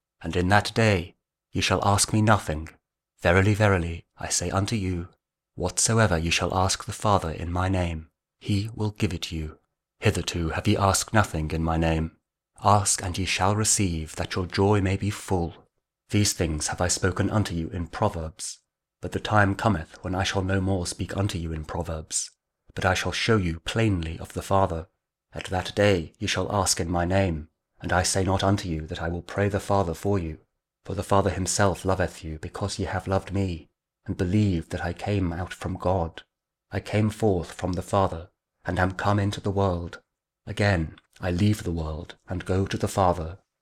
John 16: 23-28 | King James Audio Bible | Daily Bible Verses Easter To Pentecost